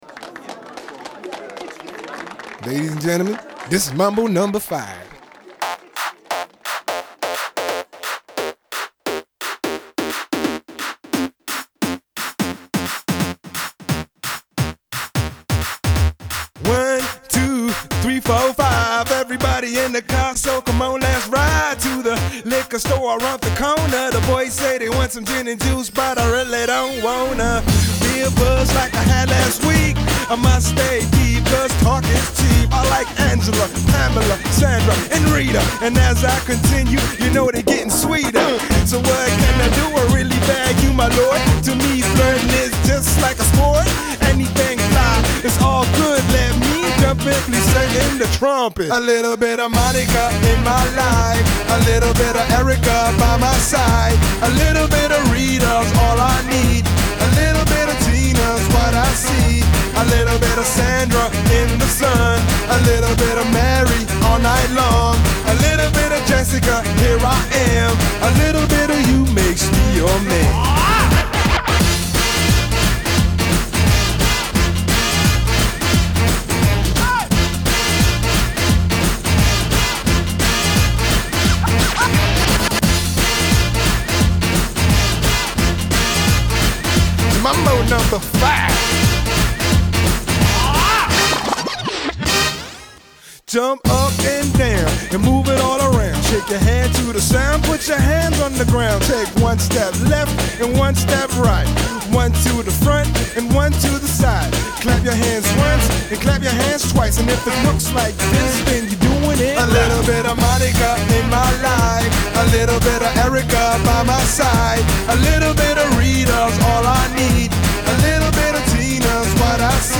Pop 90er